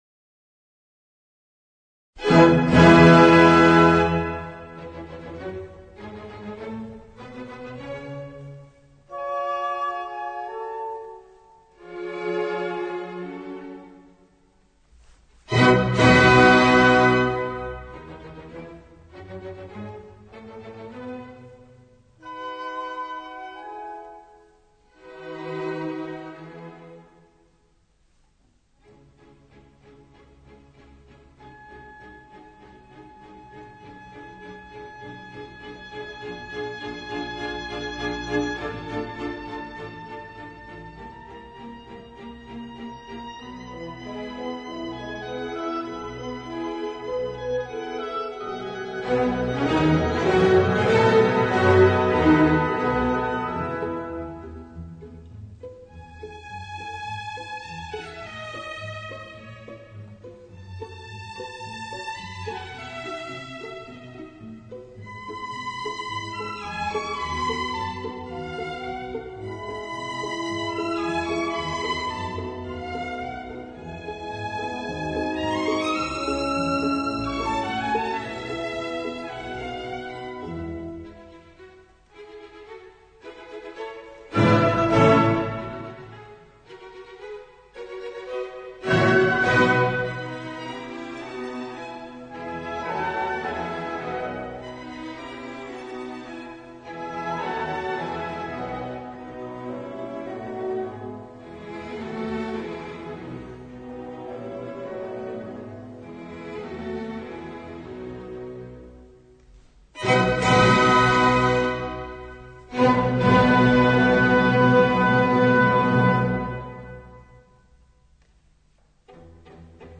音樂類型：古典音樂
與『大花版』系列相同錄音技術製作
這套專輯雖然編制不大